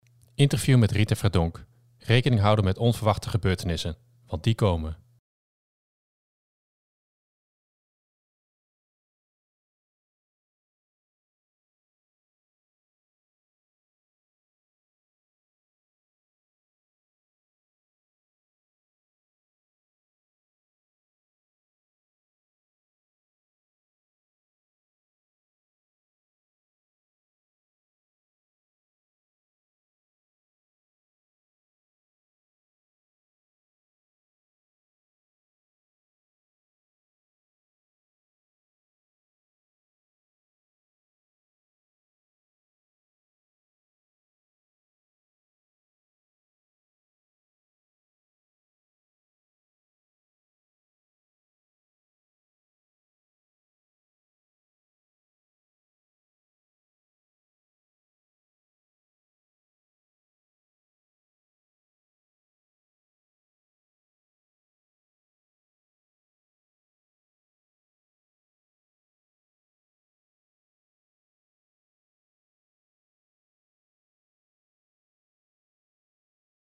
Interview met Rita Verdonk